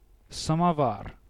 A samovar (Russian: самовар, IPA: [səmɐˈvar]